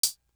Air It Out Hat.wav